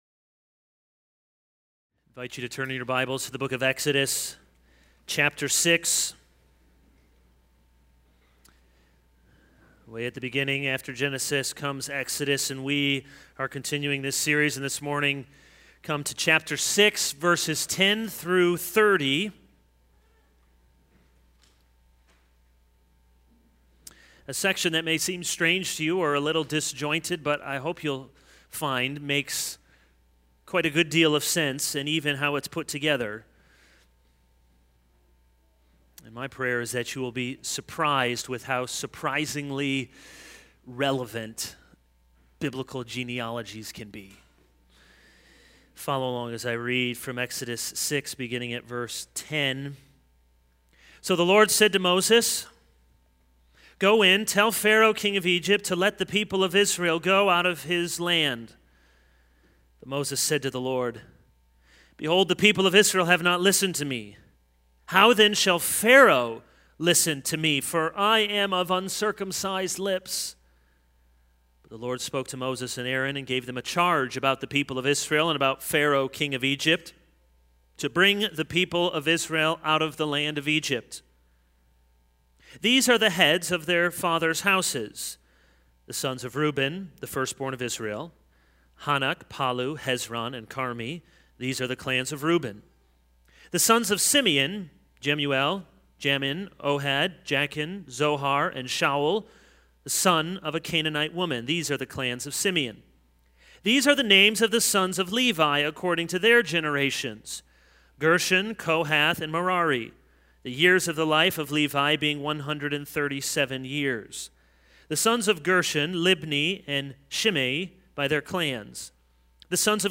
This is a sermon on Exodus 6:10-30.